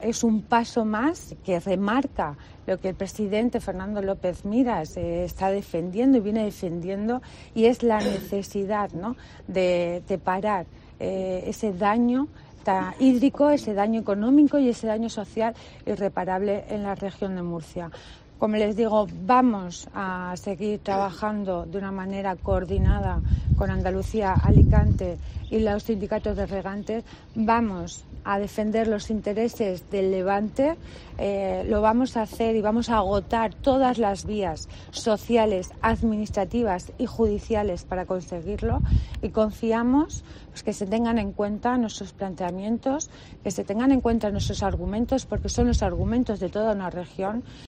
Encuentro celebrado en el parador nacional de turismo y organizado por la Cámara de Comercio de Lorca
Valle Miguélez, consejera de Empresa, Economía Social y Autónomos